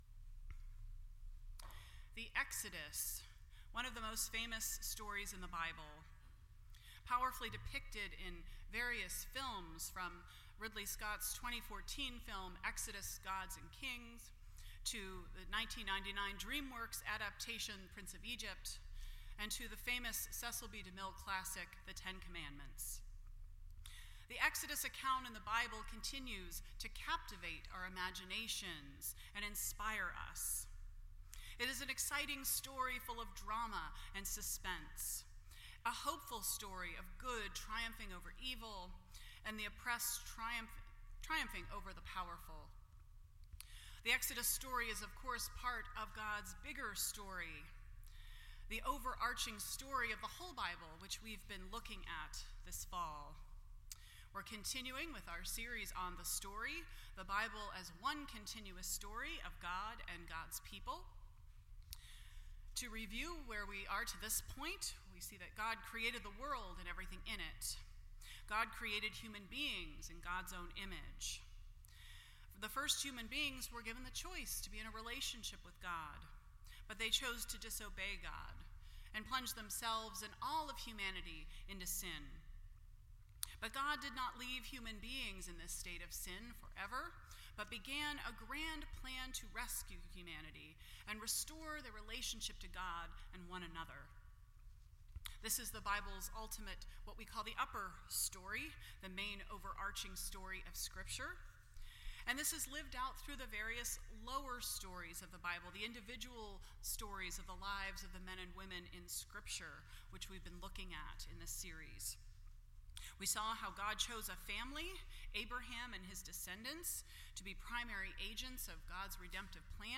The Story Service Type: World Communion Sunday %todo_render% Share This Story